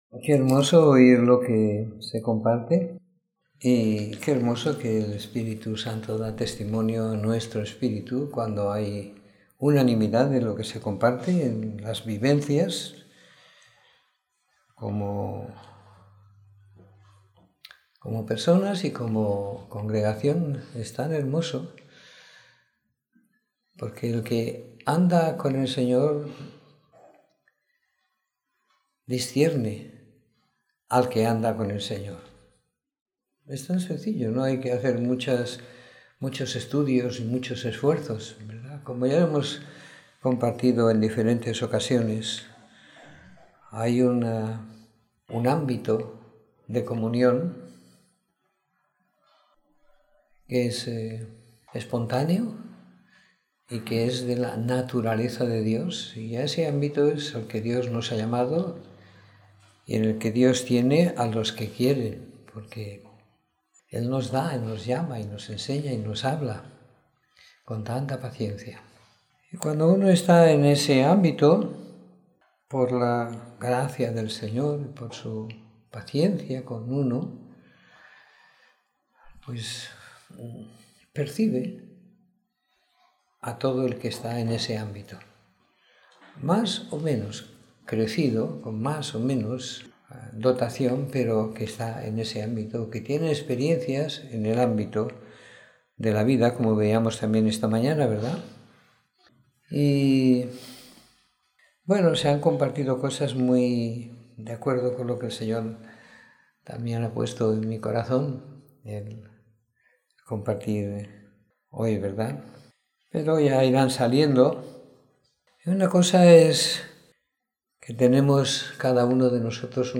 Domingo por la Tarde . 29 de Mayo de 2016